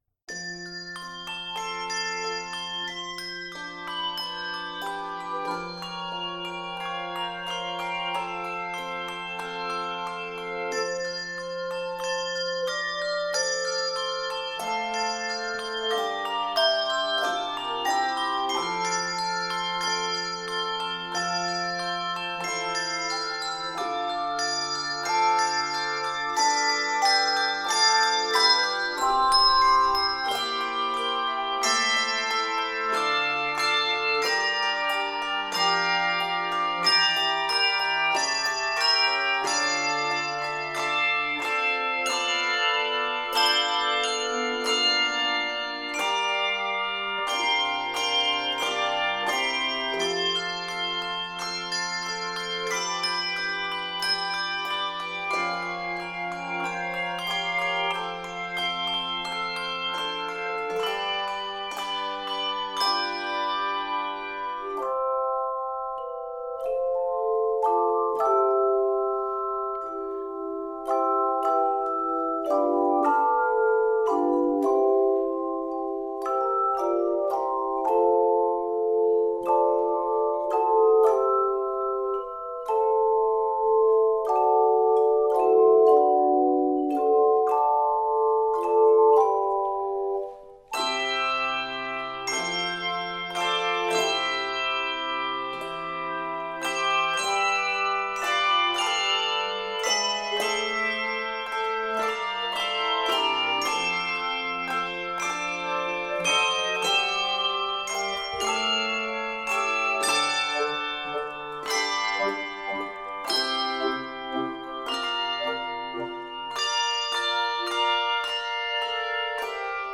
flowing yet stately setting
Keys of F Major and C Major.